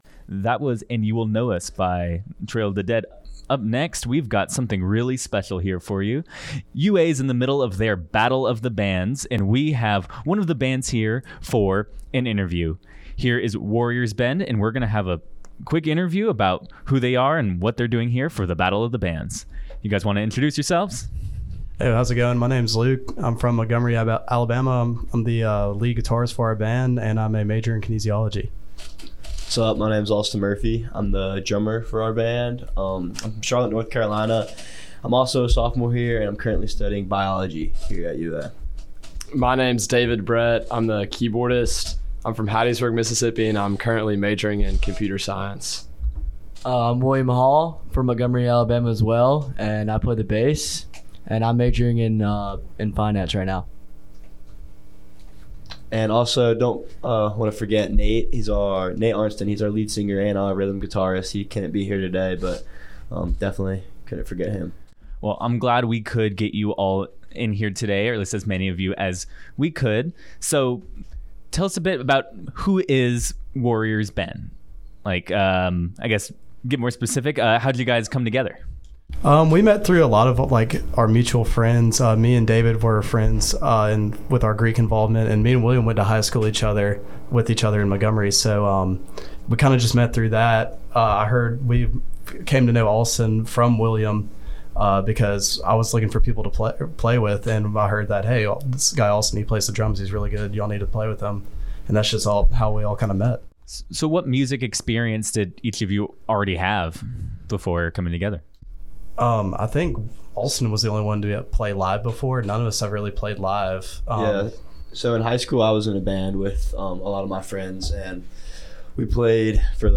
Interview w/ Warriors Bend